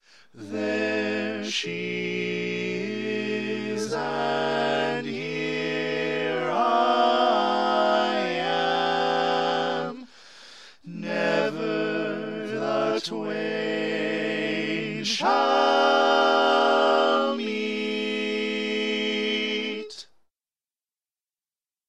Key written in: A Minor
Type: Barbershop